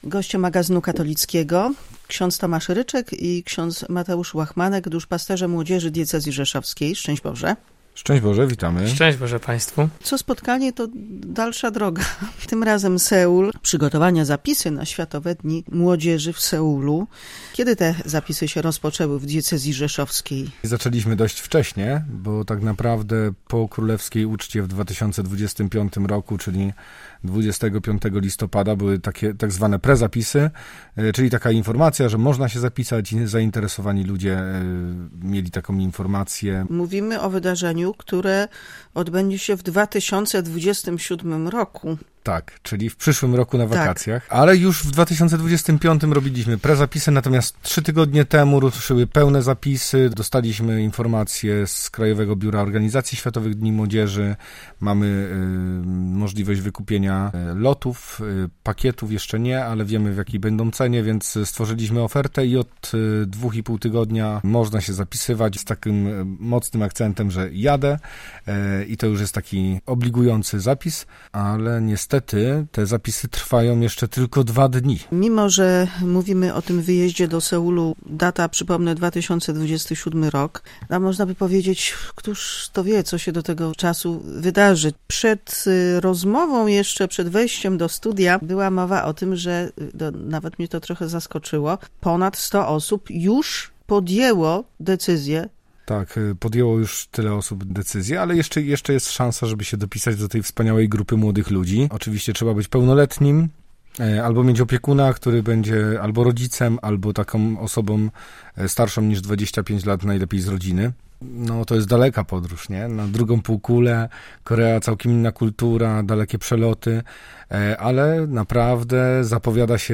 O przygotowaniach i niepowtarzalnej atmosferze tego typu międzynarodowych spotkań opowiadają goście magazynu katolickiego.